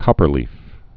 (kŏpər-lēf)